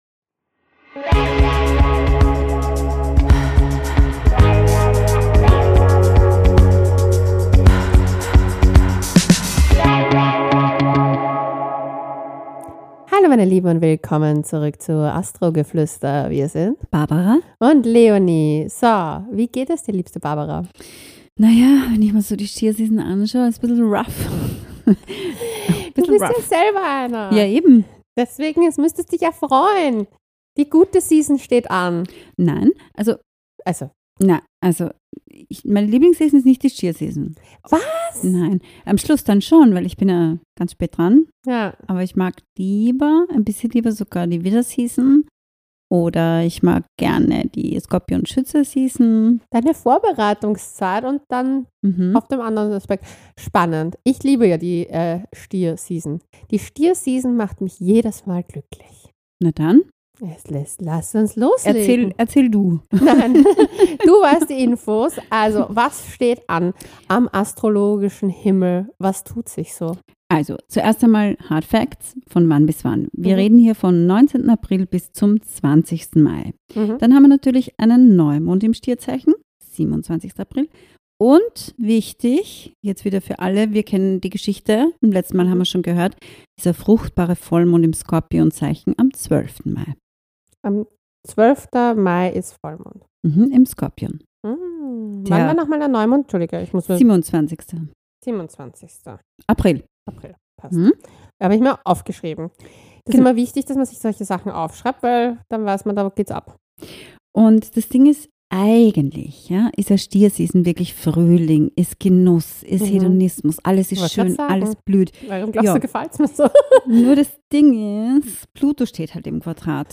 Anmerkung: Leider haben wir in dieser Folge ein kleines techisches Problem: